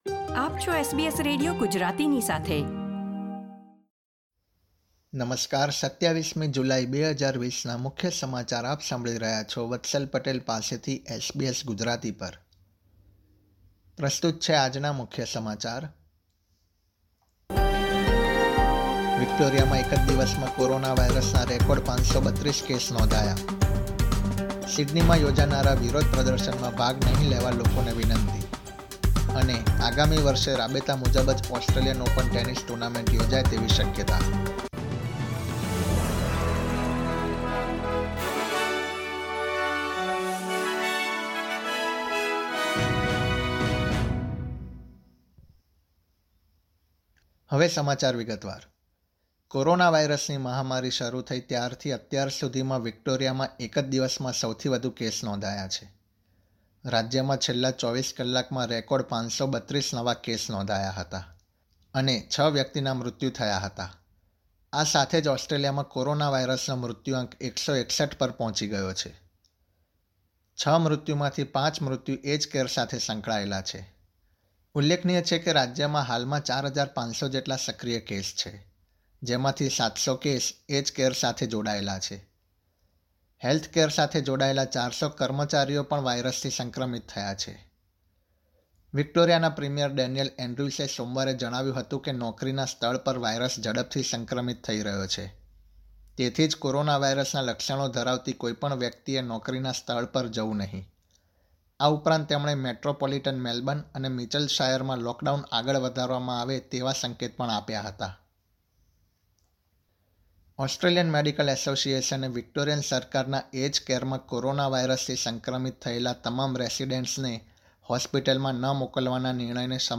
SBS Gujarati News Bulletin 27 July 2020
gujarati_2707_newsbulletin.mp3